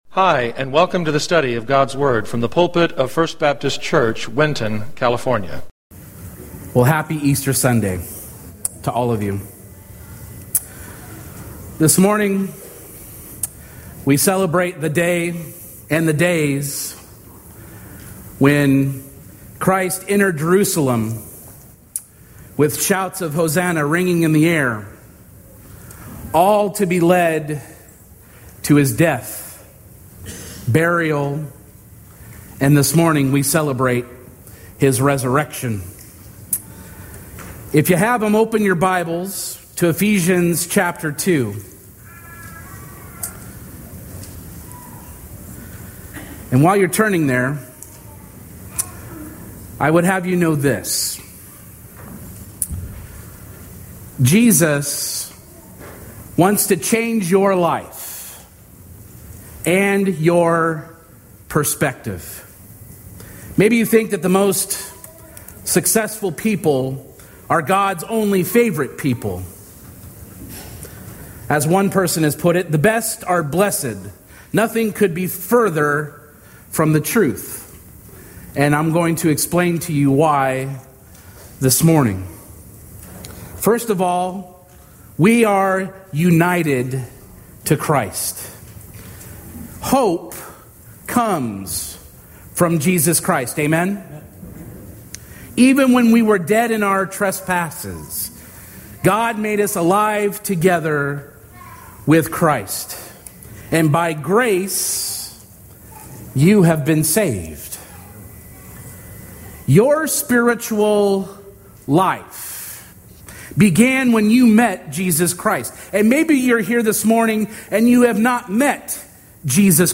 Sermons | Winton First Baptist Church